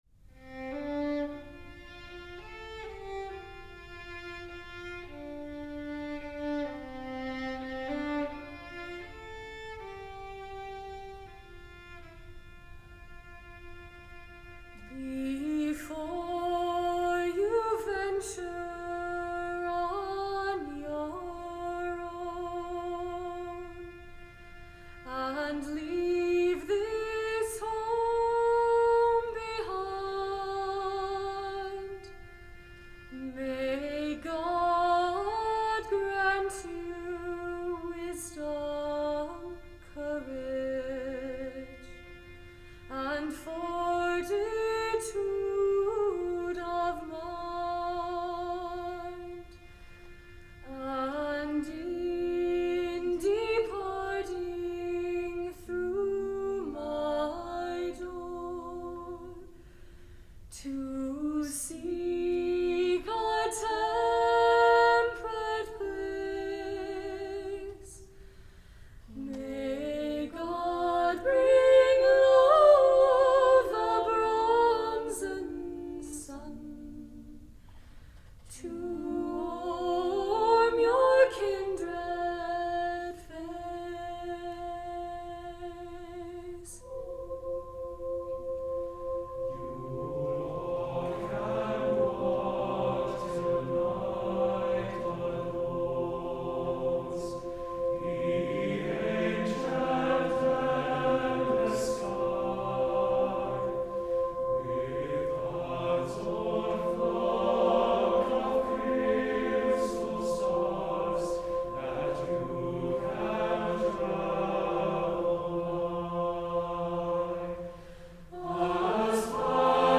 for SATB choir, C instrument